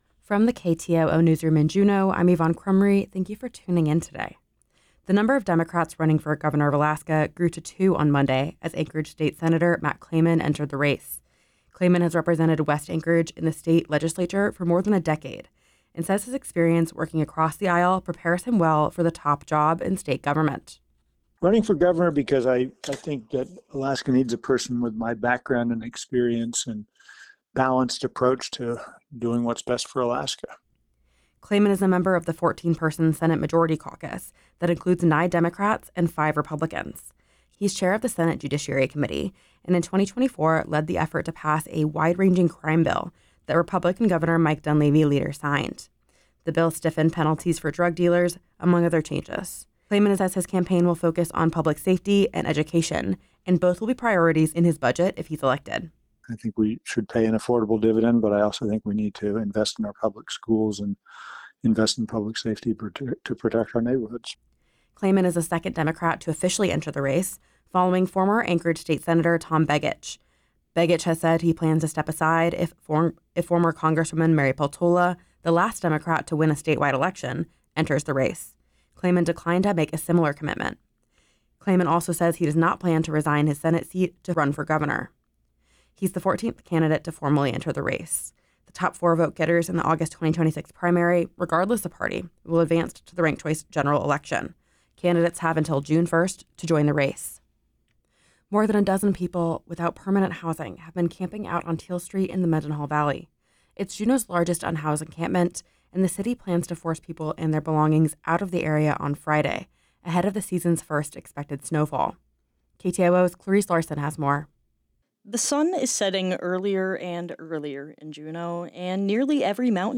Newscast – Wednesday, Nov. 12, 2025